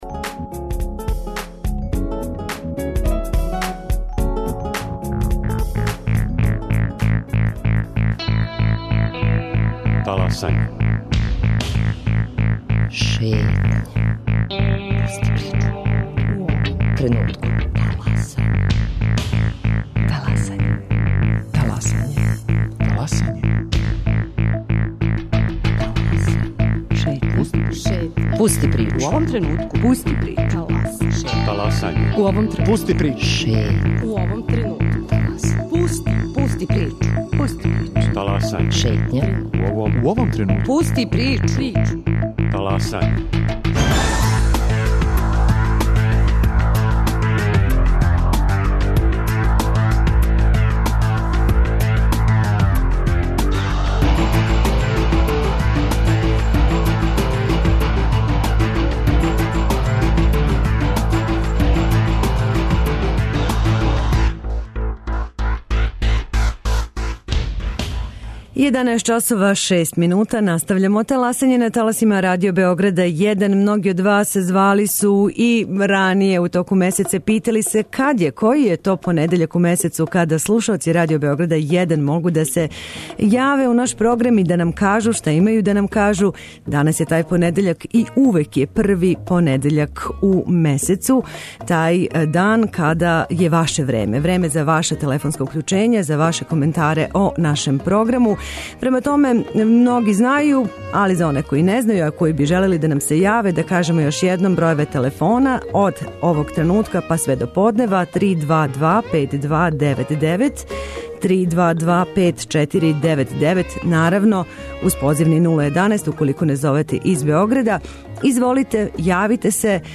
Контакт-програм.